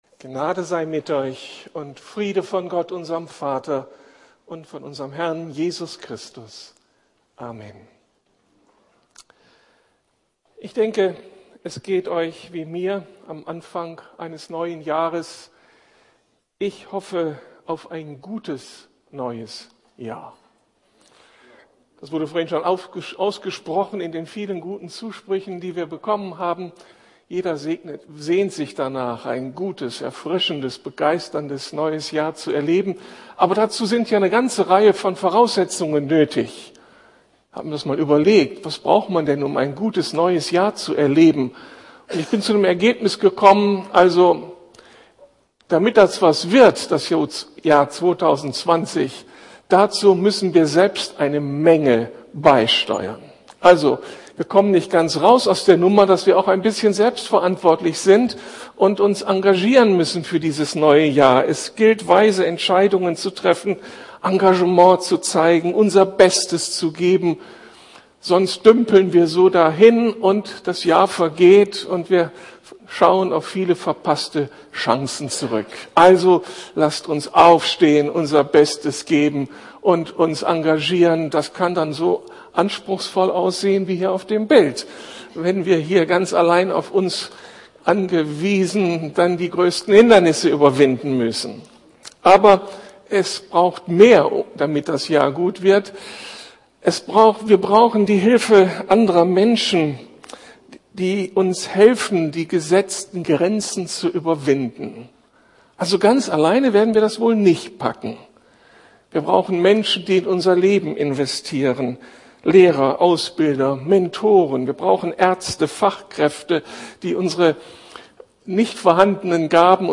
Neujahrsgottesdienst